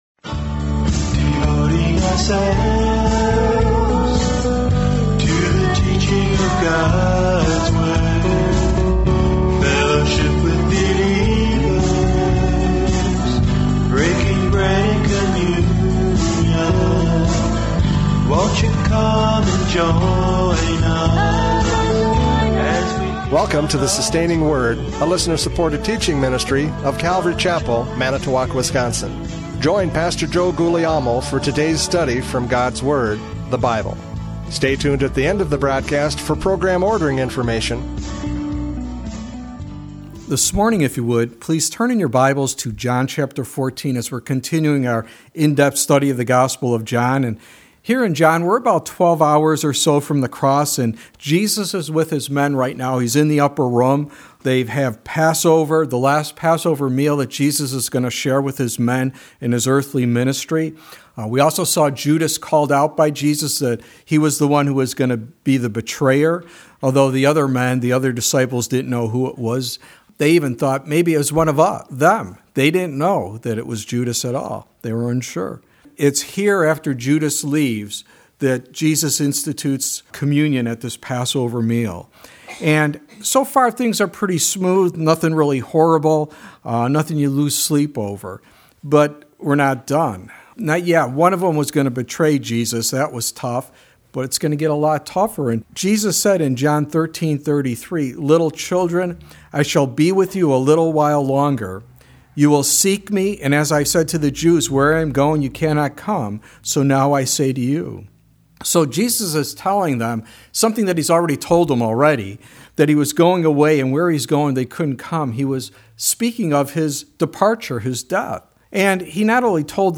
John 14:1-4 Service Type: Radio Programs « John 13:31-38 Listen Carefully!